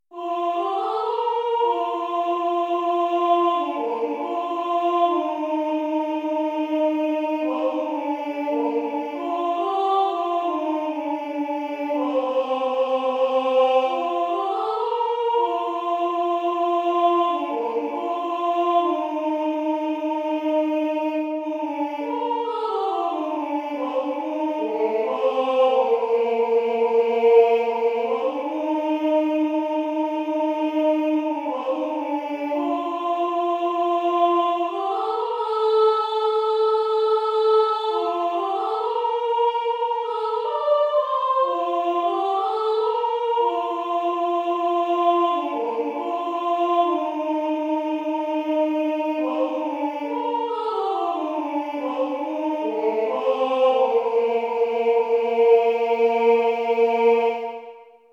gotland_sopran.mp3